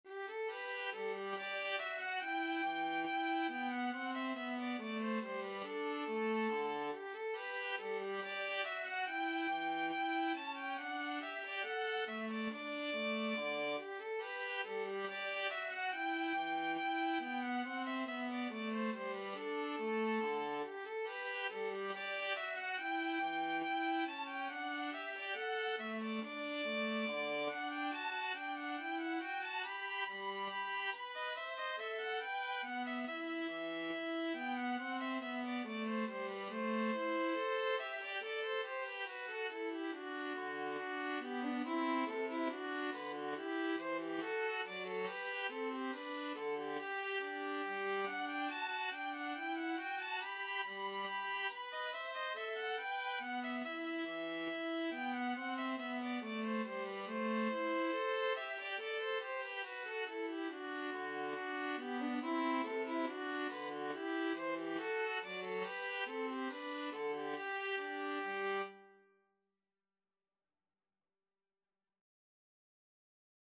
Free Sheet music for Violin-Viola Duet
4/4 (View more 4/4 Music)
G major (Sounding Pitch) (View more G major Music for Violin-Viola Duet )
Classical (View more Classical Violin-Viola Duet Music)